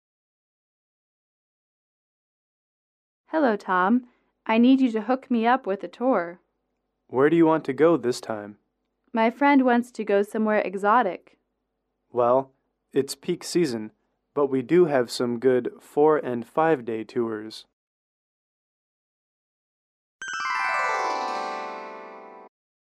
英语口语情景短对话58-1：筹划出游(MP3)